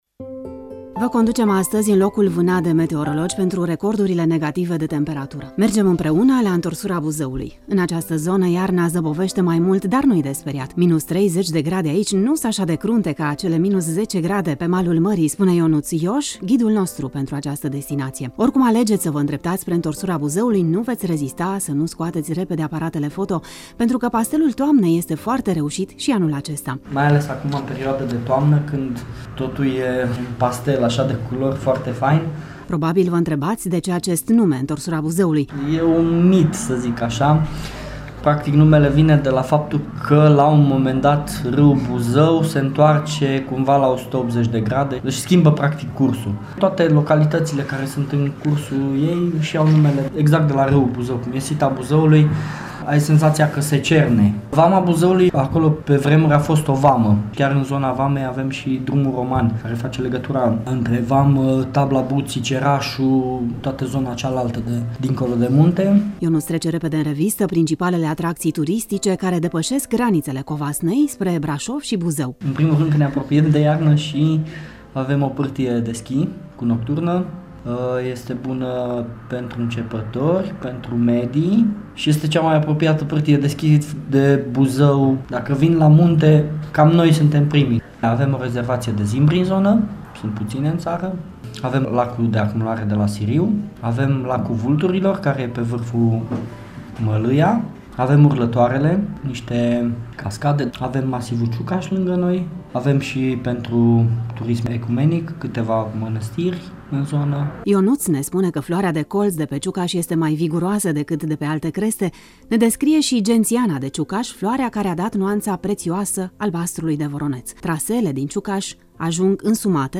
Intorsura-reportaj-scurt.mp3